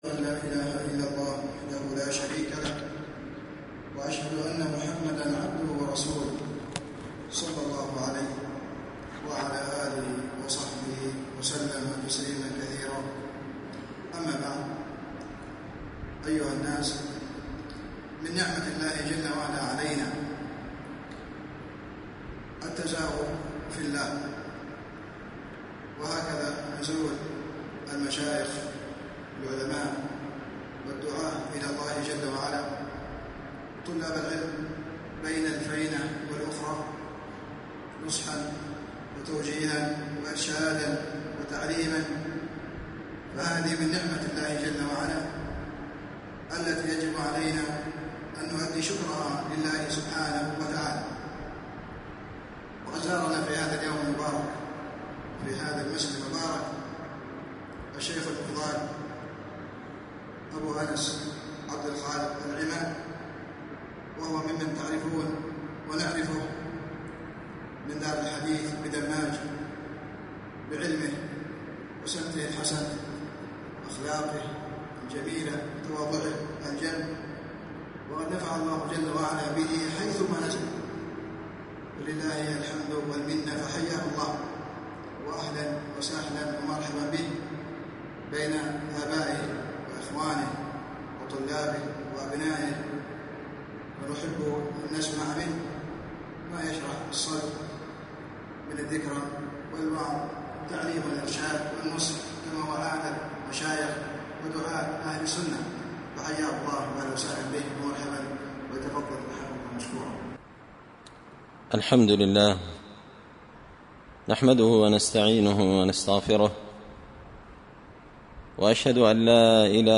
كلمة العصر بالقارة
كلمة-العصر-بالقارة.mp3